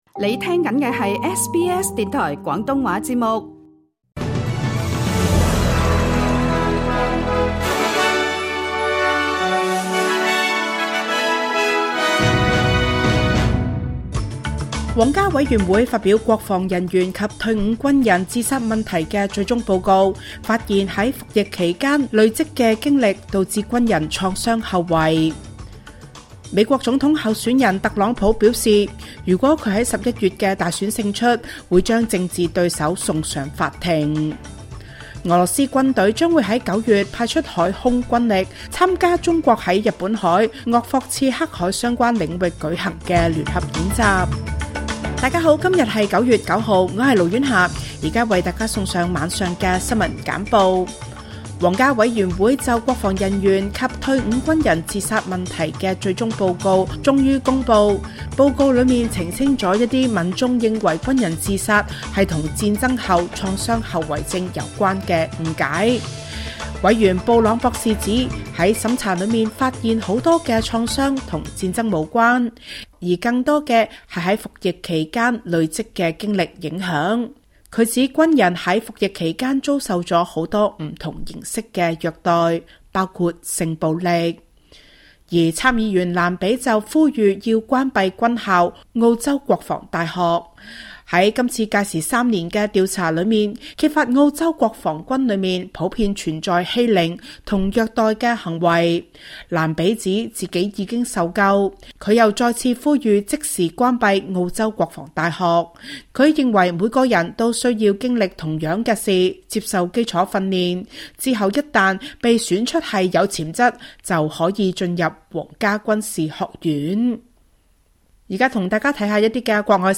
SBS 廣東話晚間新聞